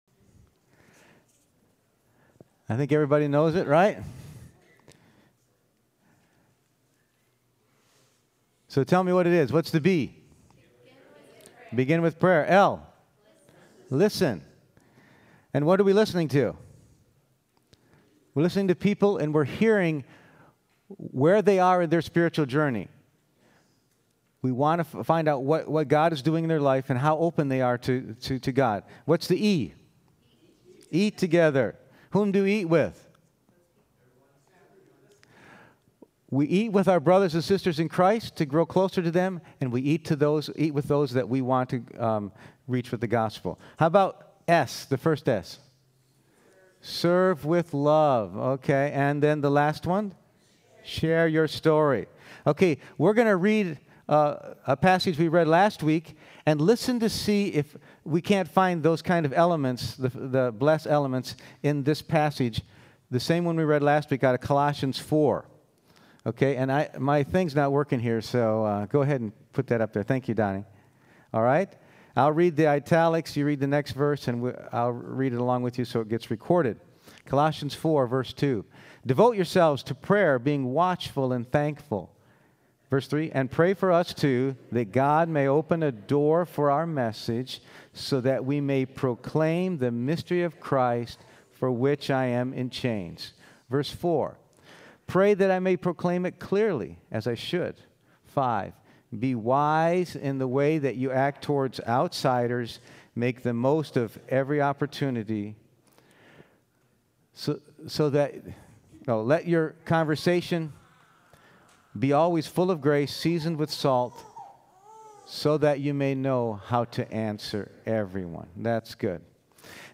B.L.E.S.S. Passage: Mark 5:18-20, John 9:25, Colossians 4:2-6 Service Type: Sunday Morning %todo_render% « Serve with Love Thank God for Halloween?